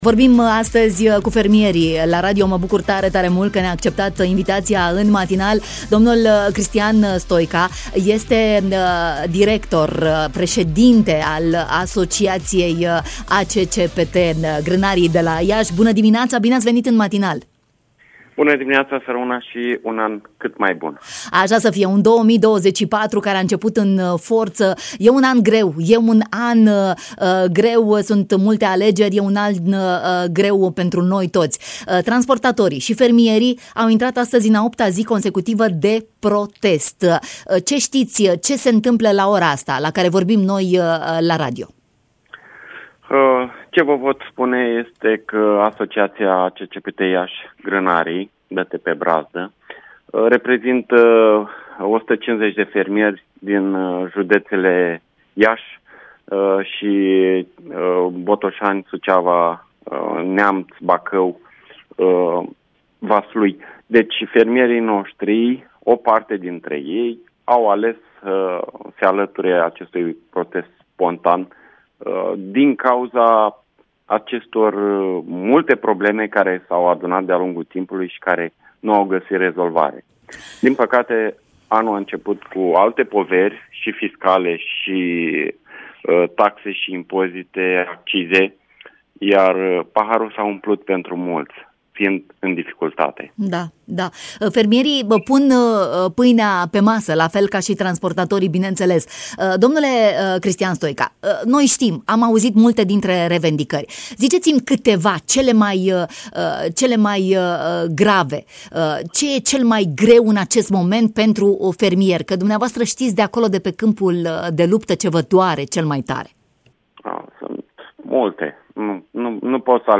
în direct la Bună Dimineața.